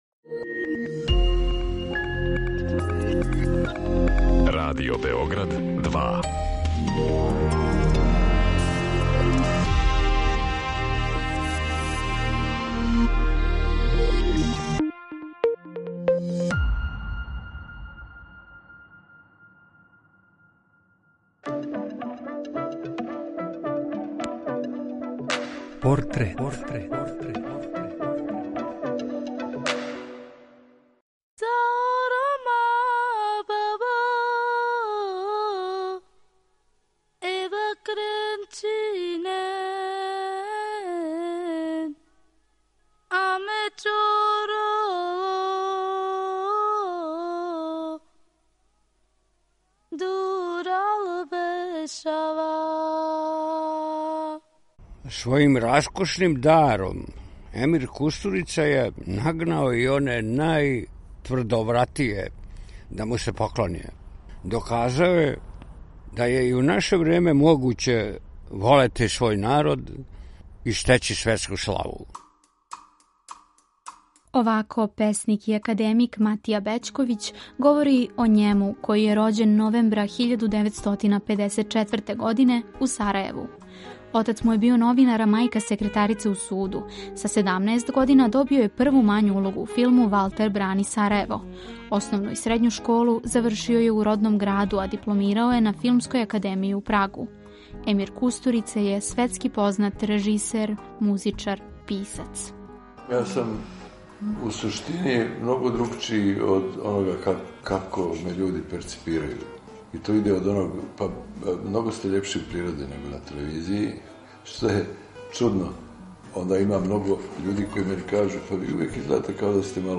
Приче о ствараоцима, њиховим животима и делима испричане у новом креативном концепту, суптилним радиофонским ткањем сачињеним од: интервјуа, изјава, анкета и документраног материјала.
Чућете шта је Емир Kустурица говорио за нашу кућу, а о њему ће говорити његови пријатељи и сарадници - академик и песник Матија Бећковић и глумац Славко Штимац.